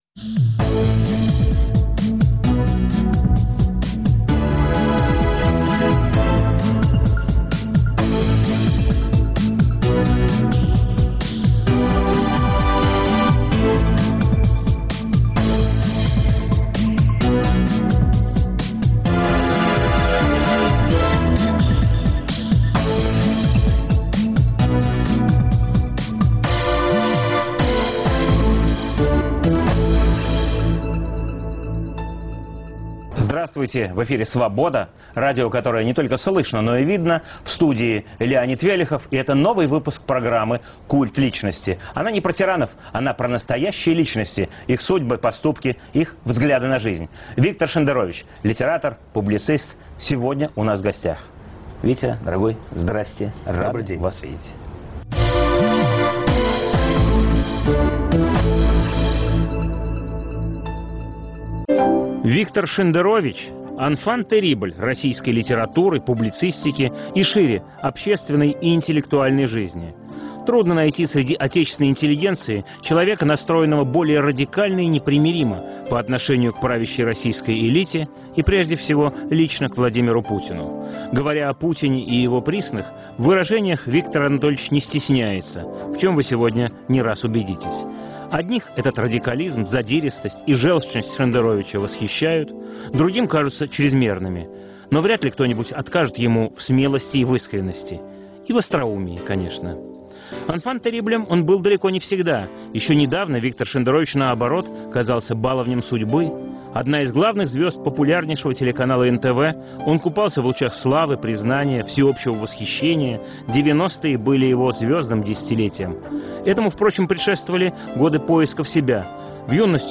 Программа о настоящих личностях, их судьбах, поступках и взглядах на жизнь. В студии писатель и публицист Виктор Шендерович.